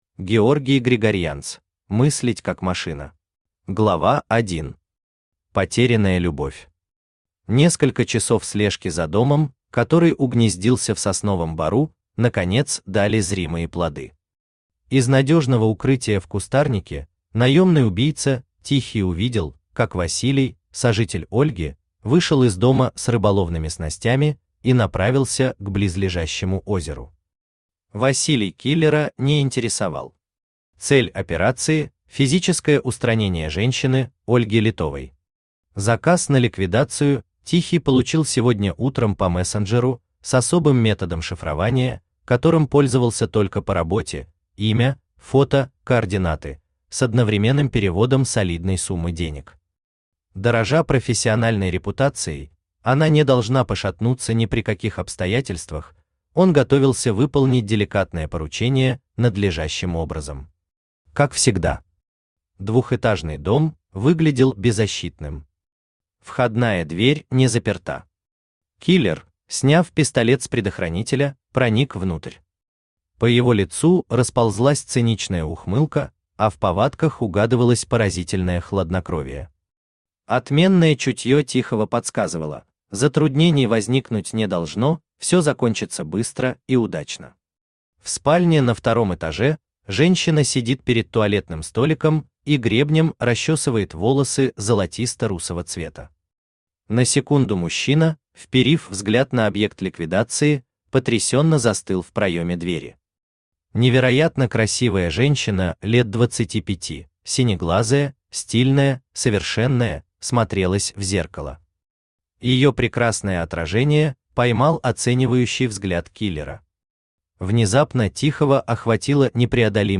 Аудиокнига Мыслить как машина | Библиотека аудиокниг
Aудиокнига Мыслить как машина Автор Георгий Григорьянц Читает аудиокнигу Авточтец ЛитРес.